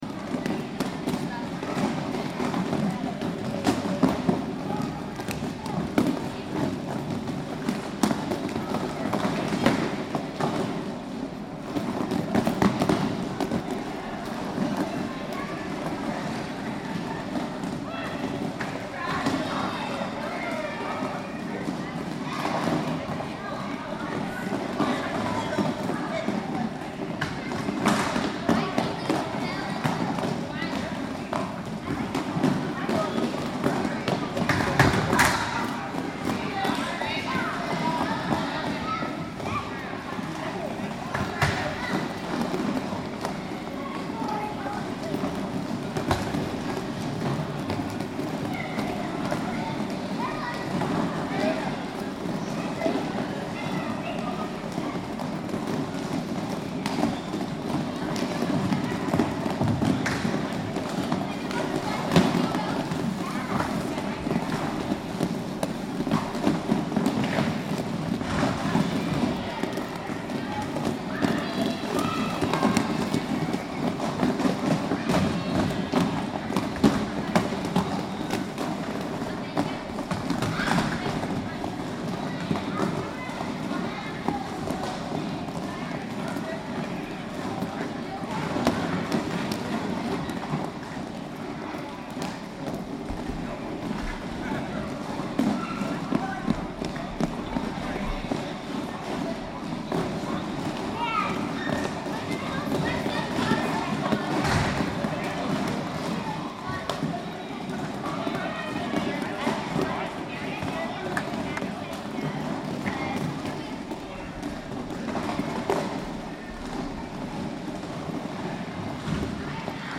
Звуки роликовых коньков
Шум роликов в скейтпарке